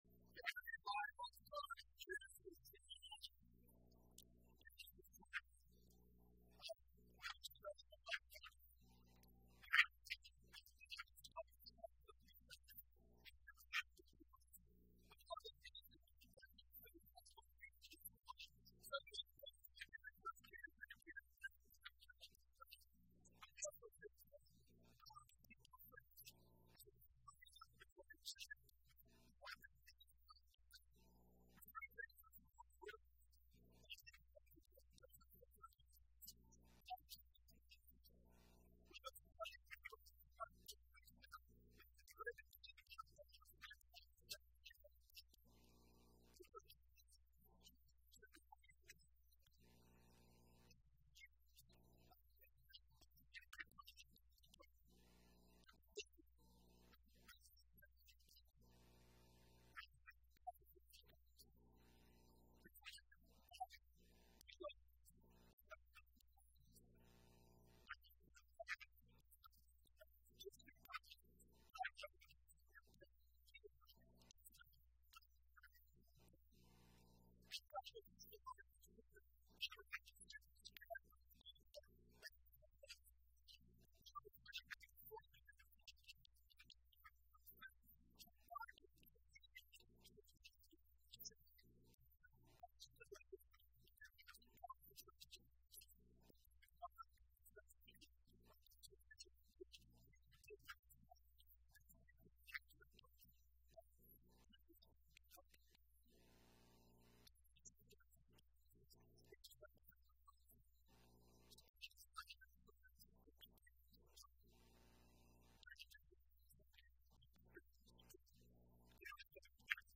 Sermons | First Assembly of God